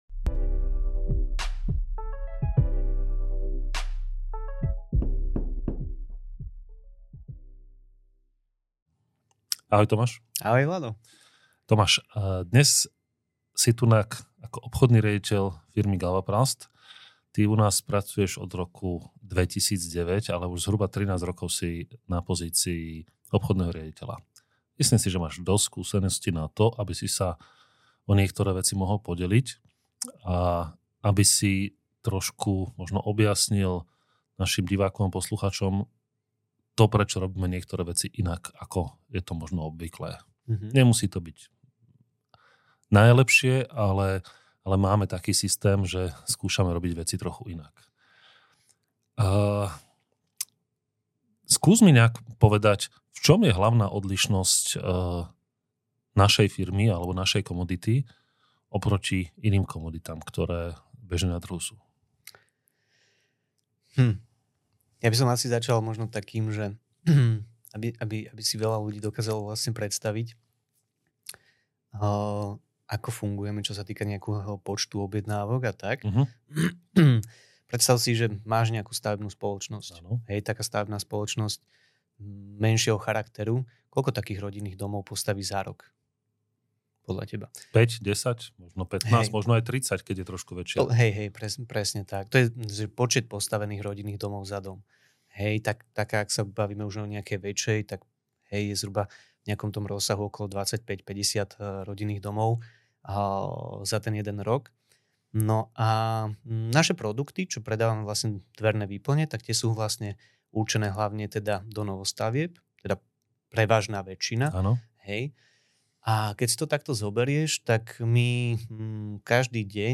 Prinášame rozhovory s ľuďmi, ktorí formujú svoje odvetvia a inšpirujú svojimi príbehmi. Odhaľujeme, čo ich poháňalo na ceste k úspechu, a zdieľame ich skúsenosti, ktoré môžu byť inšpiráciou pre váš vlastný rast.